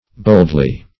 Boldly \Bold"ly\, adv. [AS. bealdl[imac]ce.]